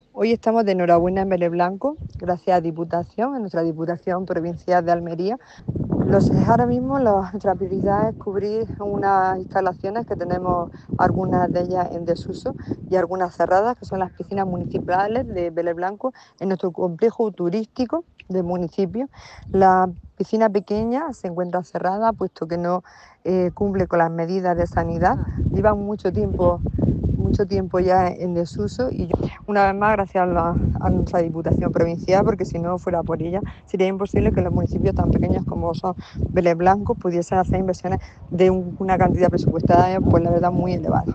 01-07_velez_blanco_alcaldesa.mp3